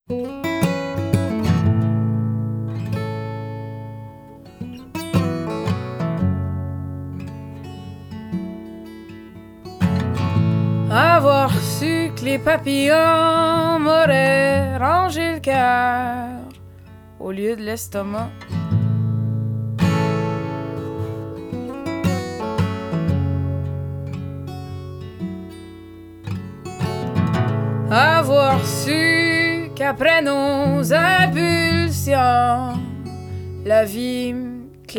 Жанр: Рок / Альтернатива / Фолк-рок